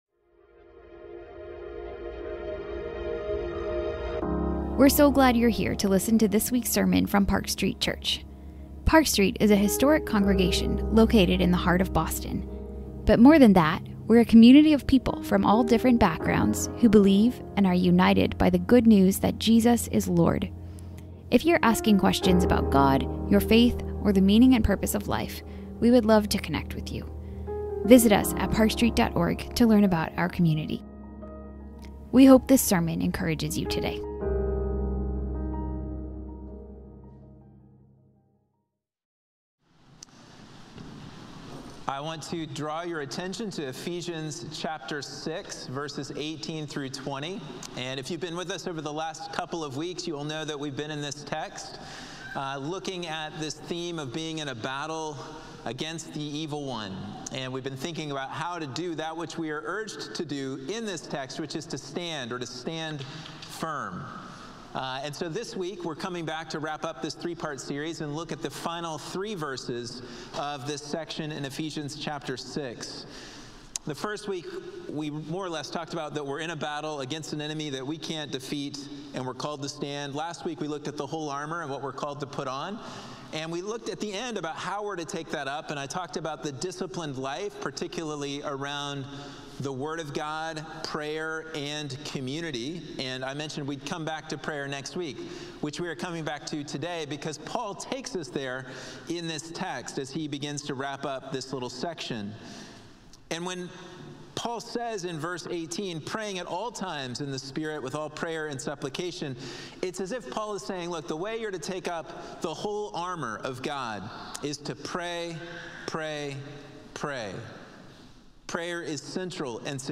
This is the third and final sermon in a short series on Ephesians 6.10-20. This message considers Paul's exhortation for us to pray at all times and the importance of prayer in helping us to put on the armor and stand firm. It also addresses the key reason for our struggles in prayer.